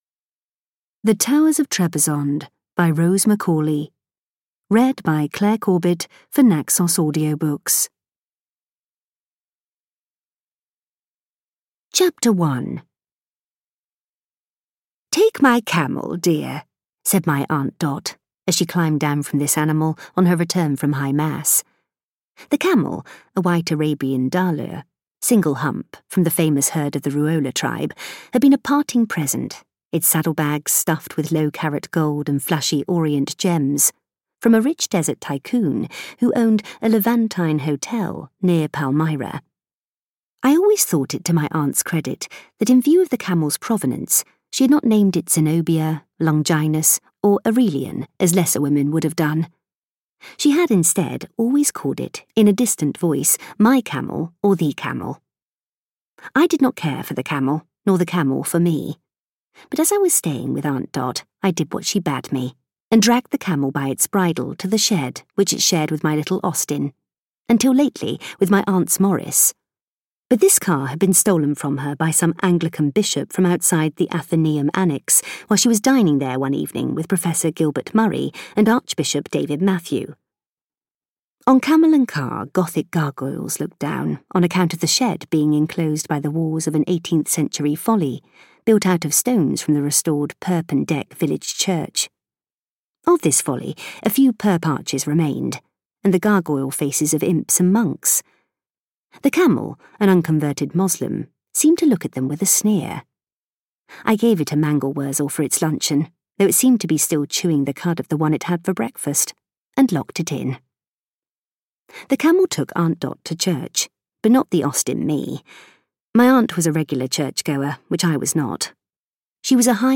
The Towers of Trebizond audiokniha
Ukázka z knihy